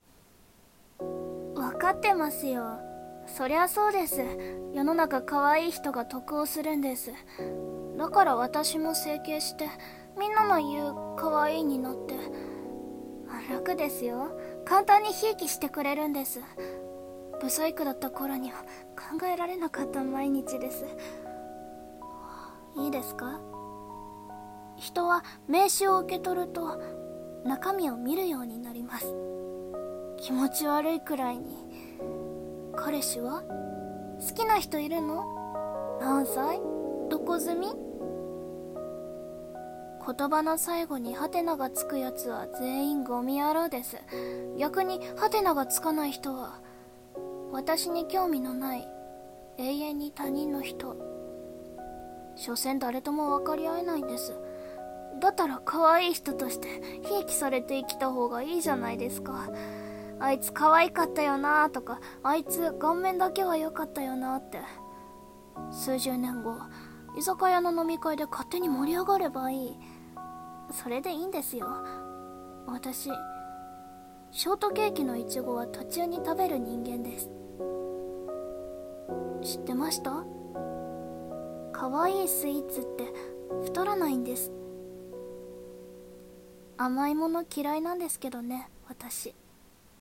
【一人用台本】可愛い人【声劇】